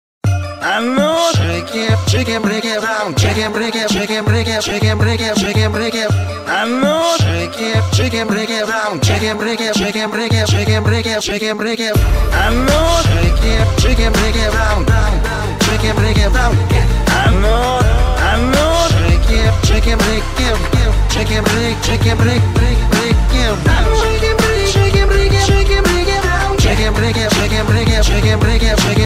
мужской голос
забавные
веселые
Mashup
phonk